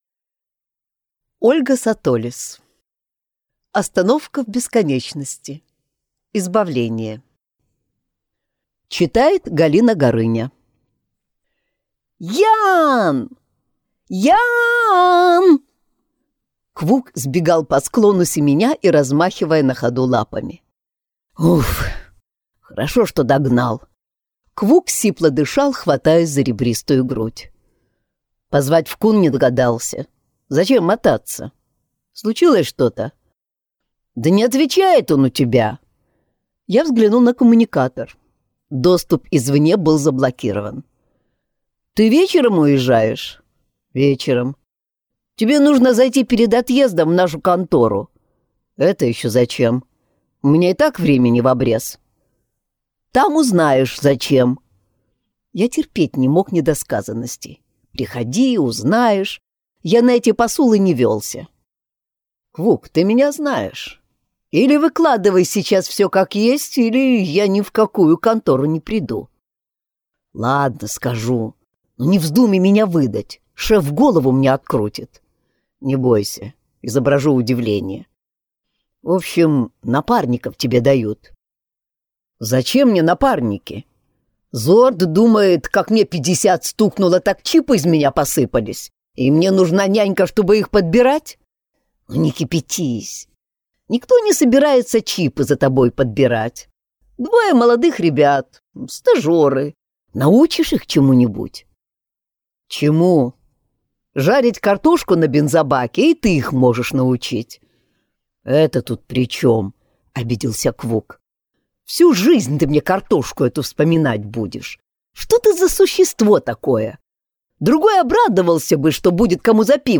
Аудиокнига Остановка в бесконечности. Избавление | Библиотека аудиокниг